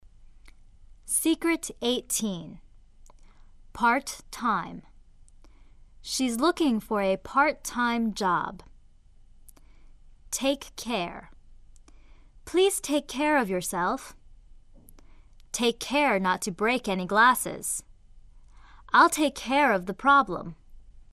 相同的两个爆破音相邻时，第一个爆破音省略，只读后面的一个爆破音。